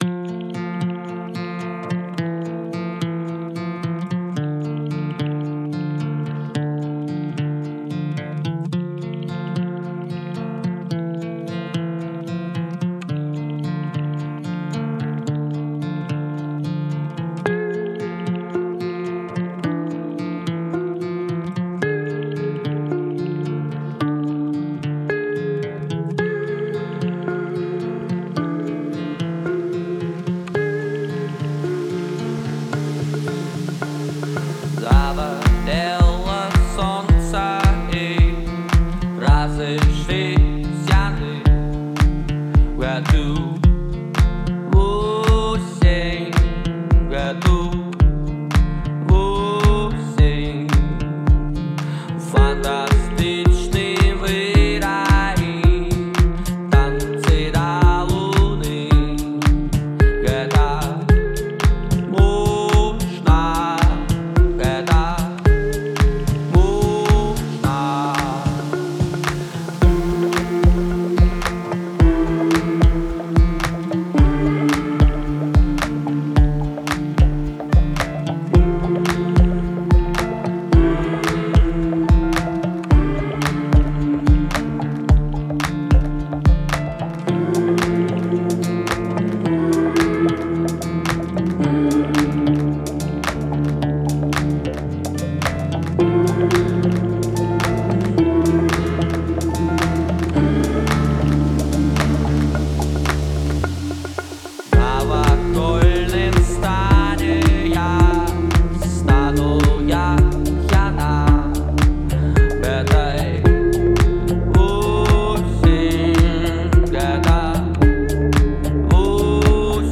што працуе ў жанры тэхна-блюзу